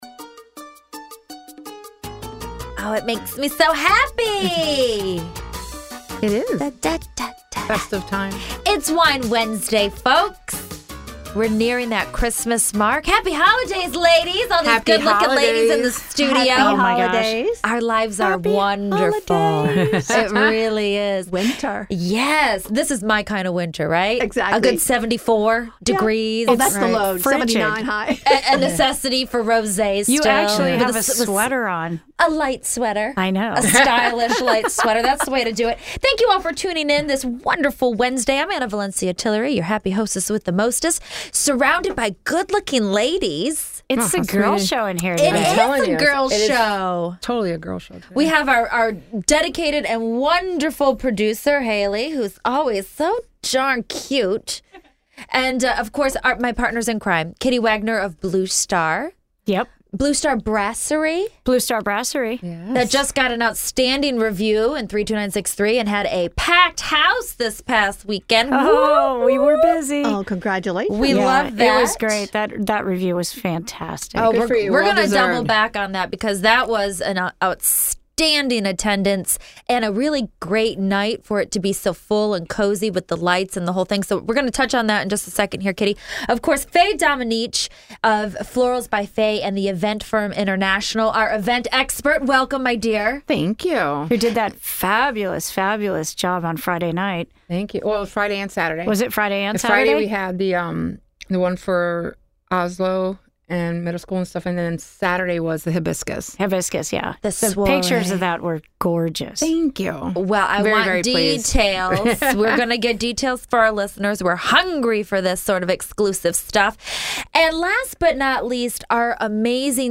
We are off to a jolly start this holiday season and all the ladies in the studio have great suggestions for you; tune in!